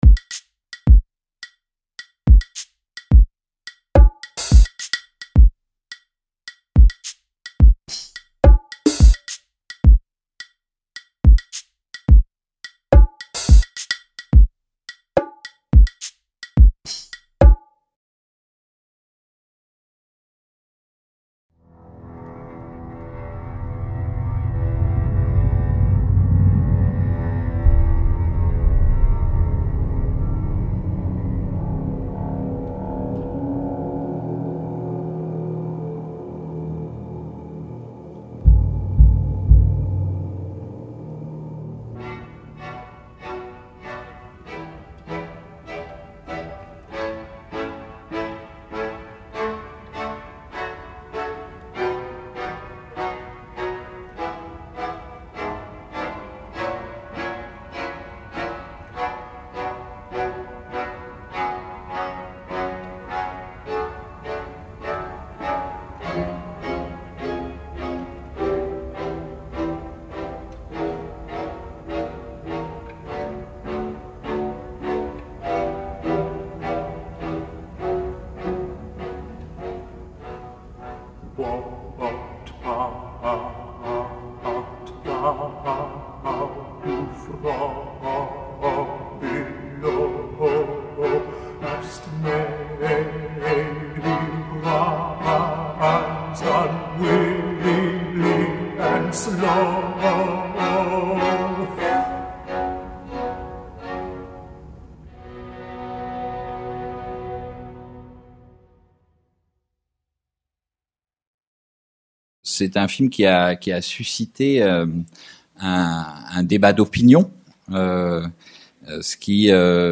Débat autour du film "Le Fils de Saul" de László NEMES | Canal U